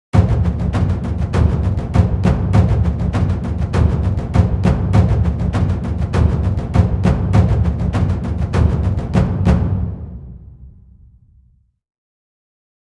دانلود صدای صحنه جنگ از ساعد نیوز با لینک مستقیم و کیفیت بالا
جلوه های صوتی